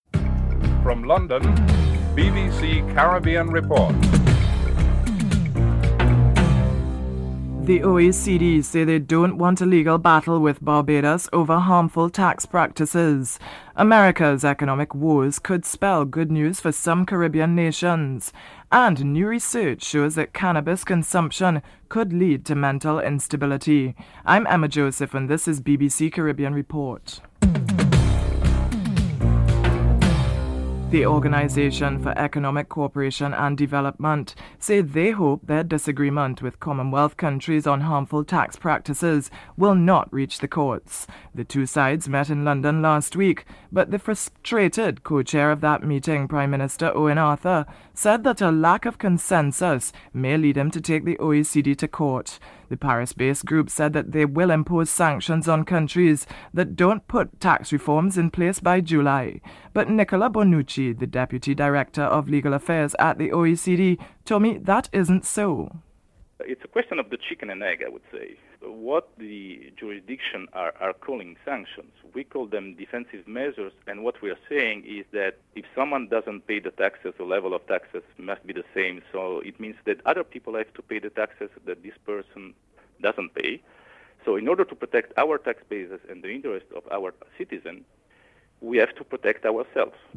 Ambassador Richard Bernal is interviewed (05:41-08:54)